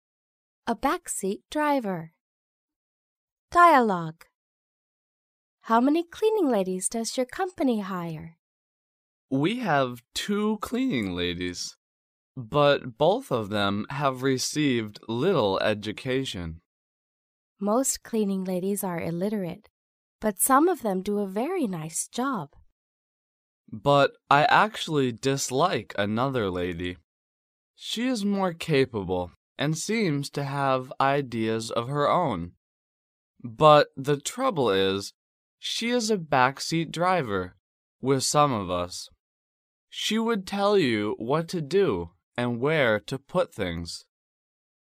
英语情景对话：